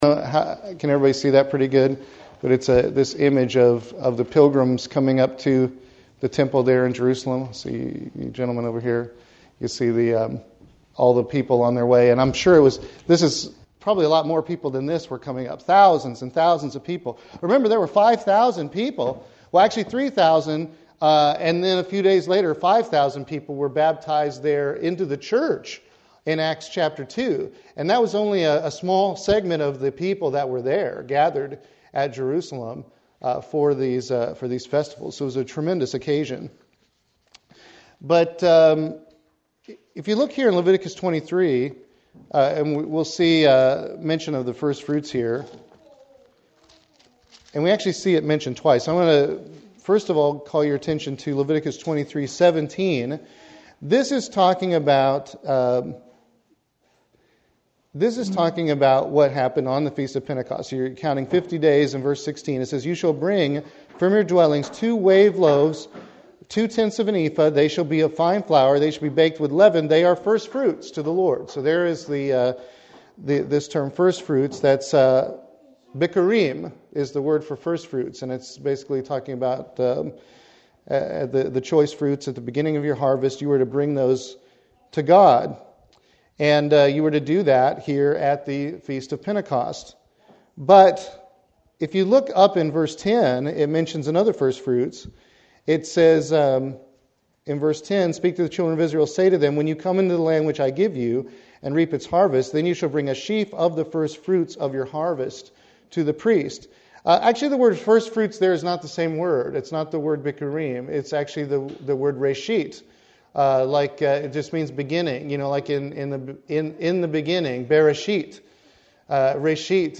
Given in Columbia - Fulton, MO
Print Review of the Firstfruits ceremony UCG Sermon Studying the bible?